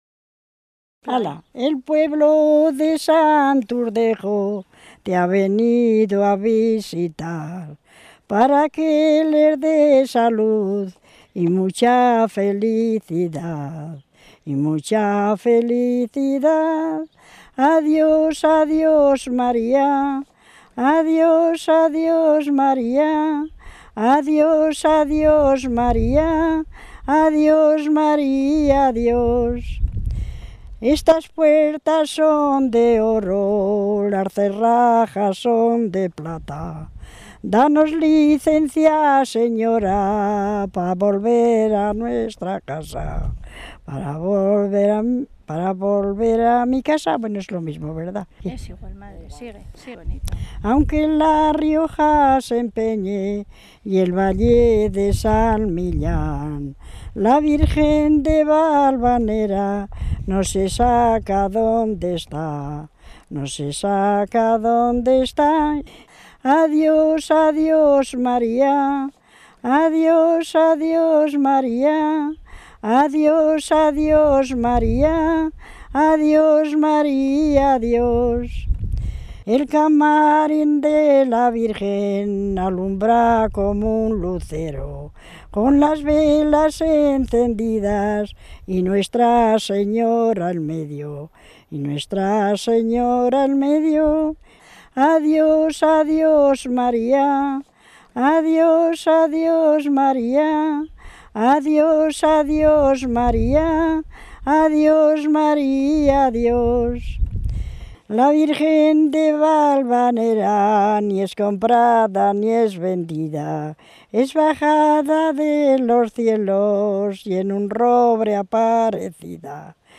Clasificación: Romerías
Contexto: Romería a Valvanera
Lugar y fecha de recogida: Santurdejo, 26 de julio de 2003